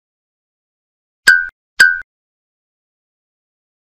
Cartoon Blink Sound Effect Free Download
Cartoon Blink